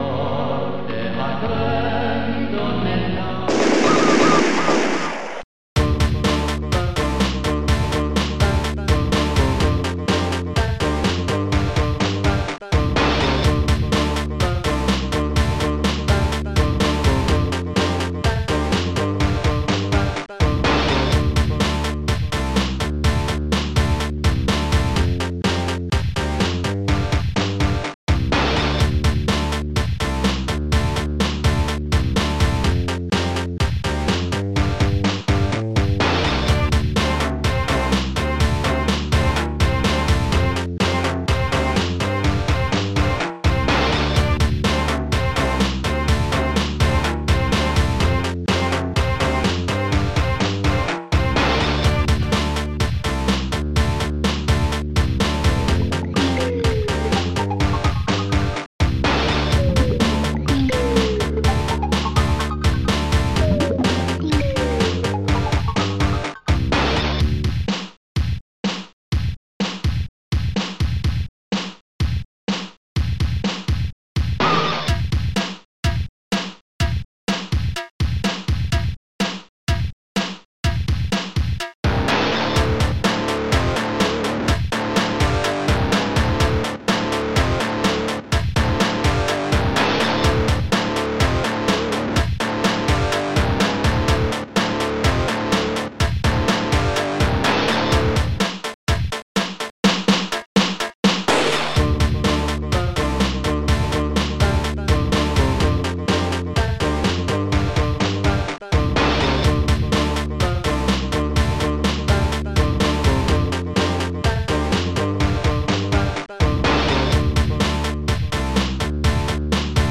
singing so wonderful